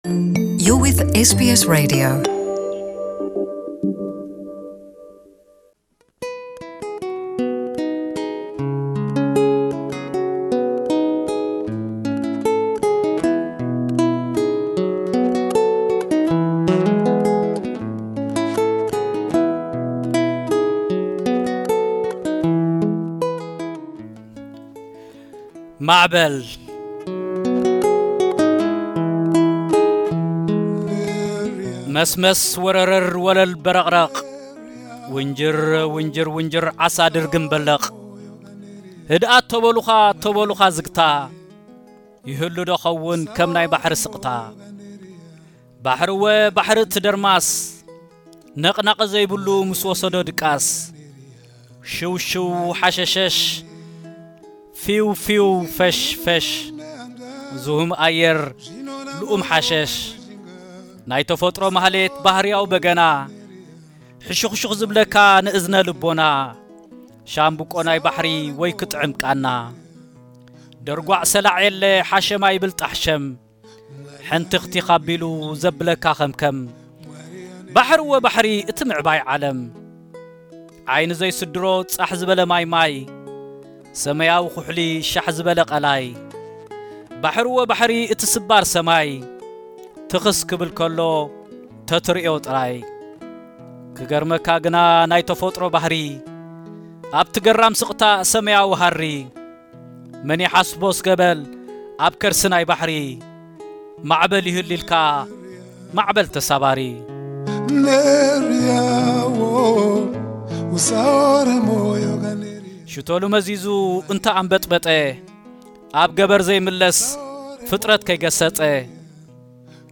ትግርኛ ግጥሚ